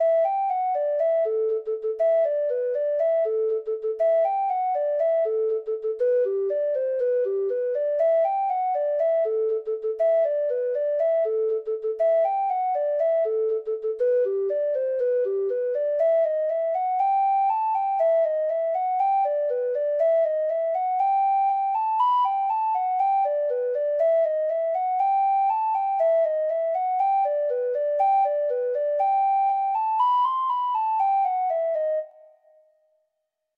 Free Sheet music for Treble Clef Instrument
Reels
Irish